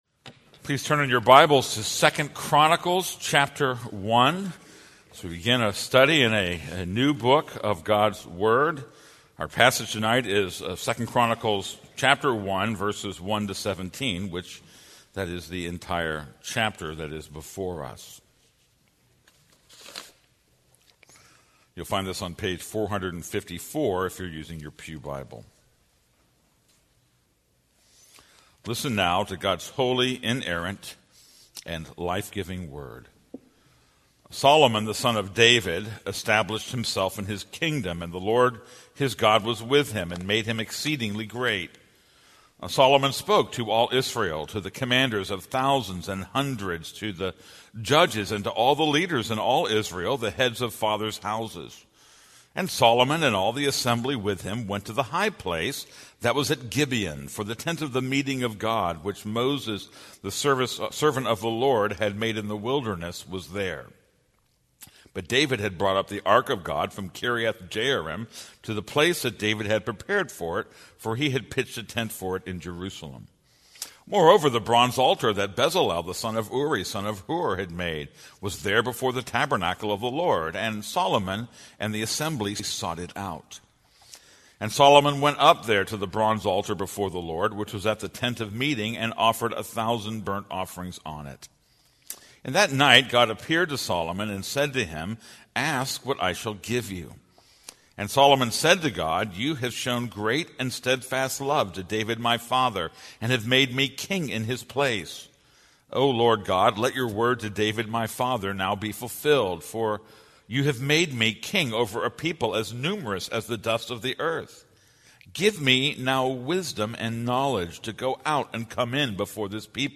This is a sermon on 2 Chronicles 1:1-17.